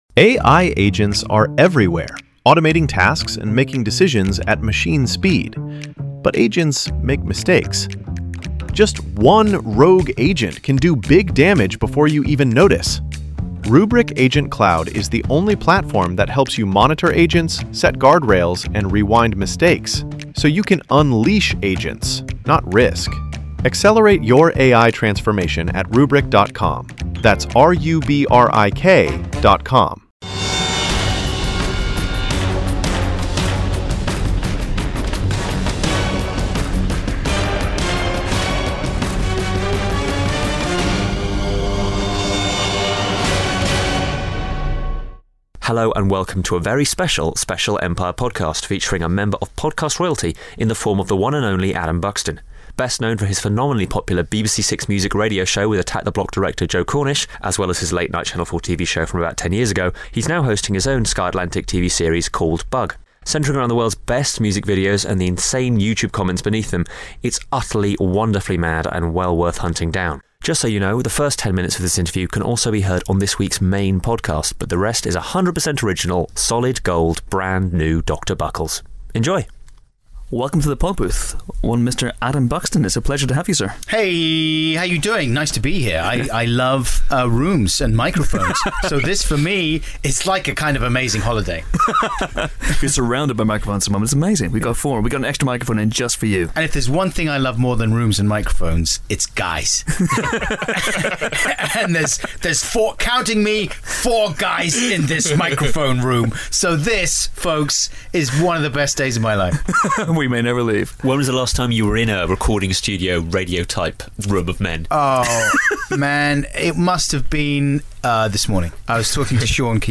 Here then is a half-hour long special with the bearded podcast king